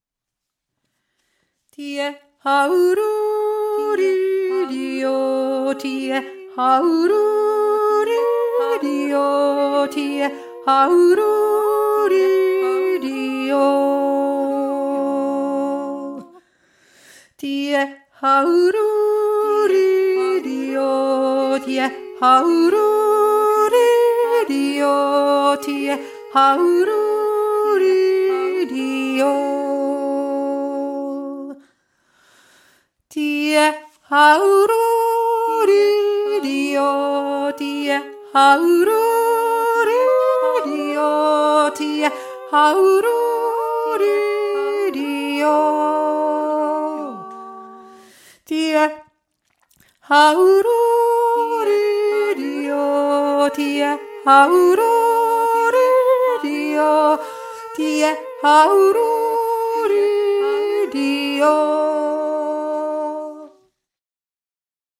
1. Stimme